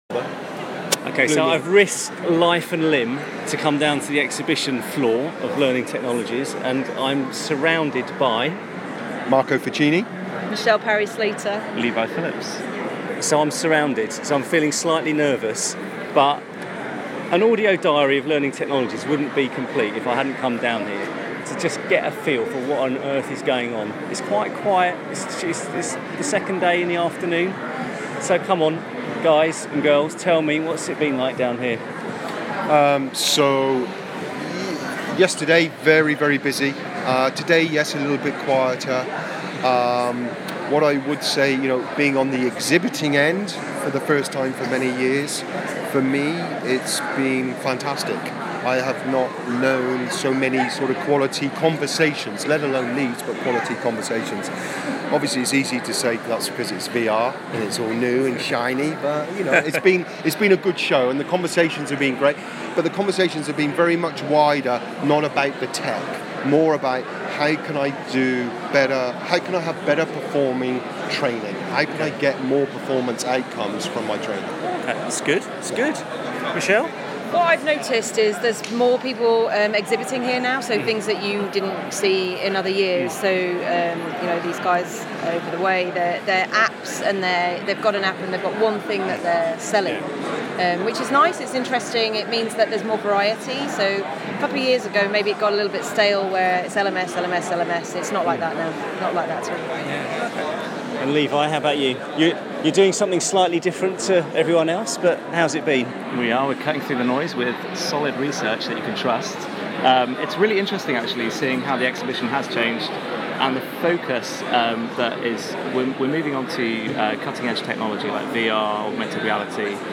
Learning Technologies 2017: The view from the exhibition hall